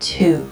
Countdown_02.wav